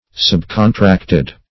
Search Result for " subcontracted" : The Collaborative International Dictionary of English v.0.48: Subcontracted \Sub`con*tract"ed\, a. 1.